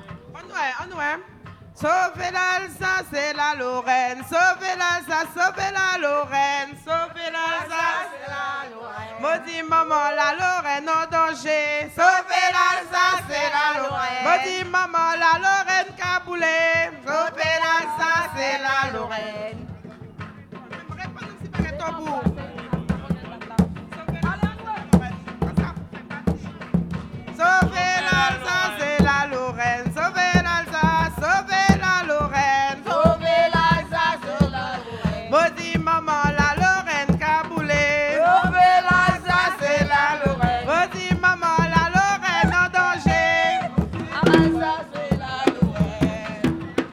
danse : kasékò (créole)
Pièce musicale inédite